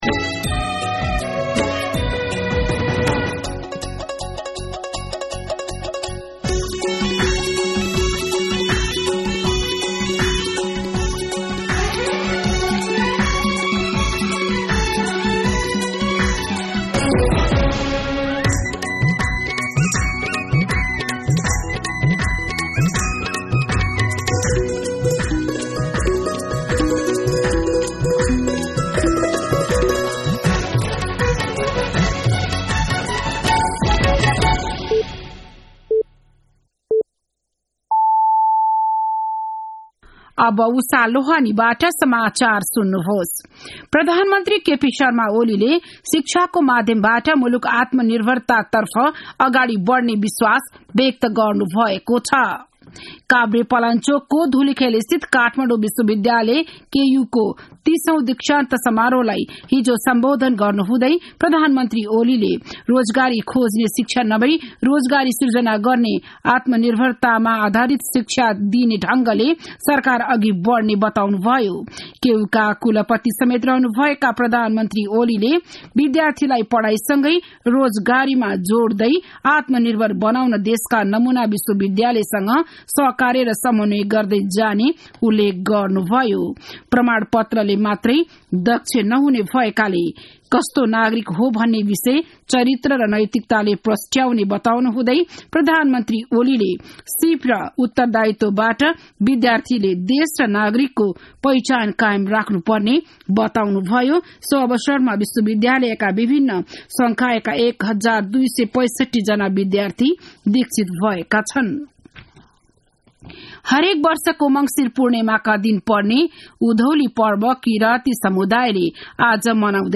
बिहान ११ बजेको नेपाली समाचार : १ पुष , २०८१
11am-Nepali-News-1.mp3